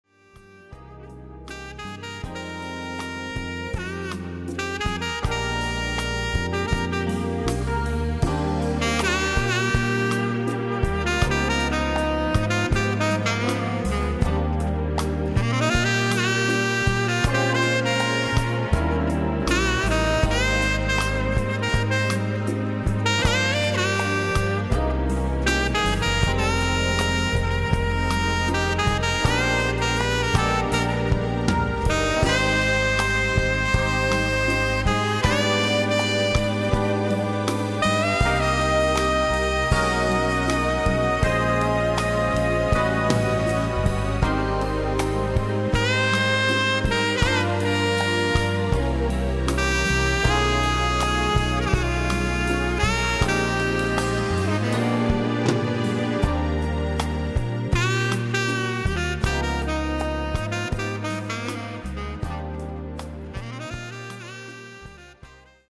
Latin jazz
Category: Little Big Band
Style: Bolero
Solos: tenor sax feature doubles: alto sax on flute